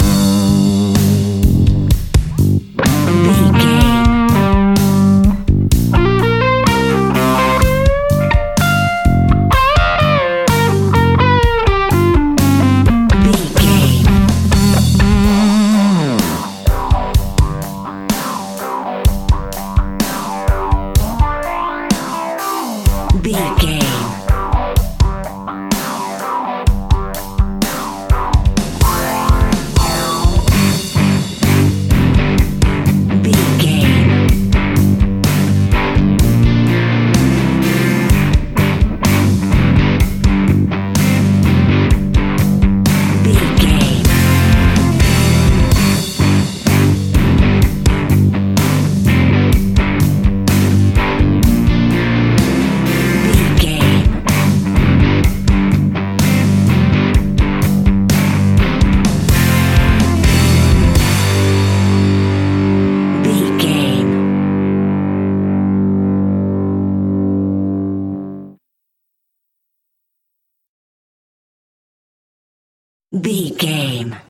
Ionian/Major
energetic
driving
happy
bright
electric guitar
bass guitar
drums
hard rock
blues rock
distortion
rock instrumentals
heavy drums
distorted guitars
hammond organ